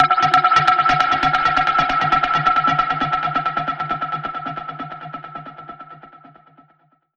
Index of /musicradar/dub-percussion-samples/134bpm
DPFX_PercHit_E_134-08.wav